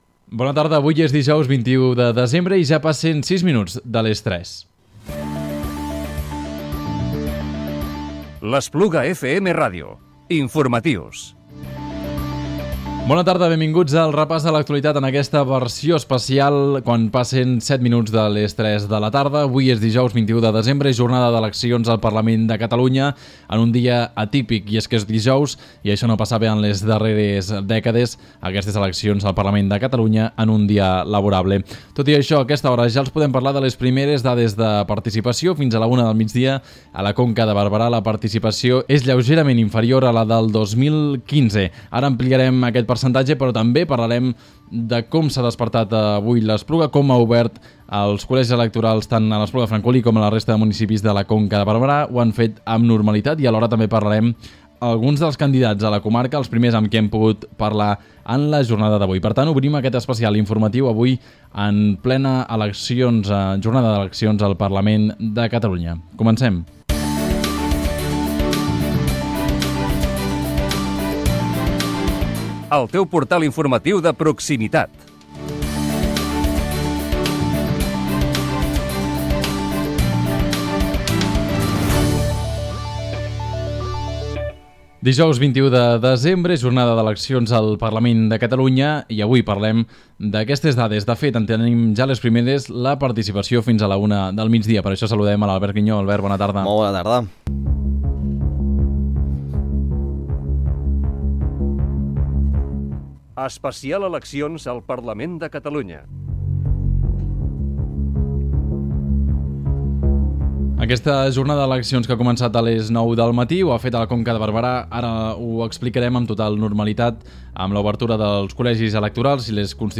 Edició especial de l'Informatiu Diari amb motiu de les eleccions al Parlament de Catalunya que se celebren aquest dijous. Detallem els primers resultats de participació i escoltem les primeres valoracions de candidats de la Conca de Barberà.